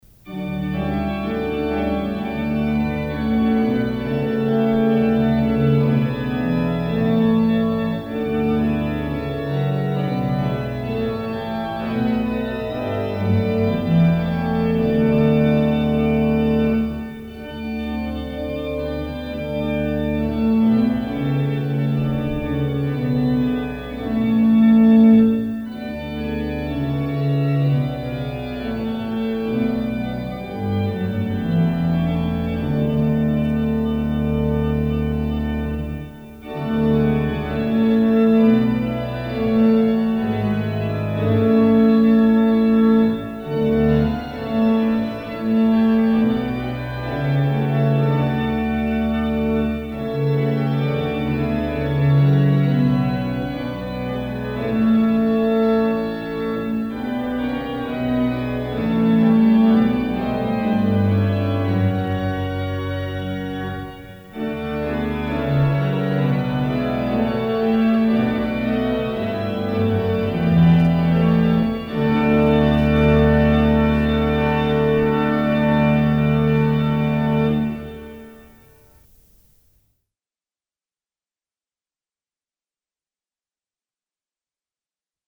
Dit stuk begint met de prestanten 8,4,3 en 2 van hoofd en rugwerk samen met de mixtuur en scherp. De melodie is in het pedaal met de trompet 16. Daarna is alleen het rugwerk te horen.
In de laatste regel van het koraal wordt ook de horizontale trompet opengetrokken.
Reger-Ein-Feste-Burg-kort-koraalvoorspel.mp3